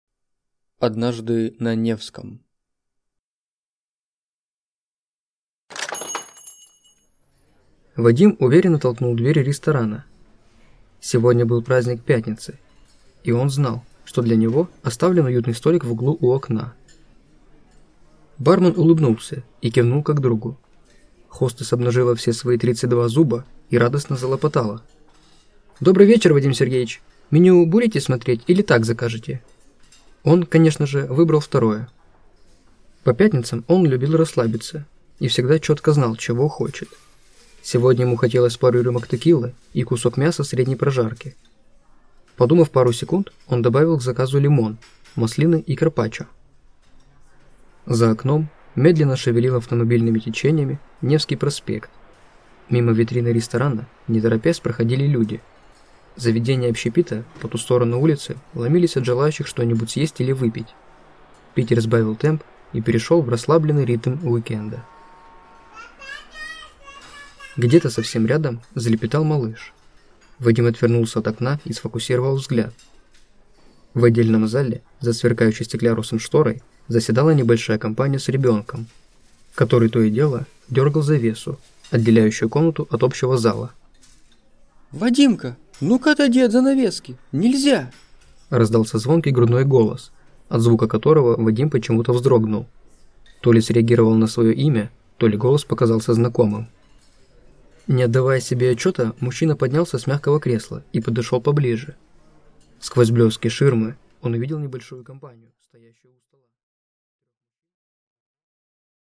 Аудиокнига Коготь вечной ночи | Библиотека аудиокниг